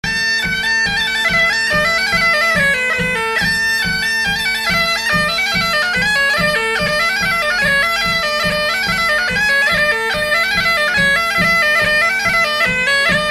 Sur l'cabinet' à ma grand-mère Détails de l'archive Titre Sur l'cabinet' à ma grand-mère Origine du titre : enquêteur Note Enregistrement effectué lors de la fête de la veuze, le 22 octobre 1989 à Fonteclose (La Garnache).
Résumé Instrumental
danse : branle : courante, maraîchine
Pièce musicale inédite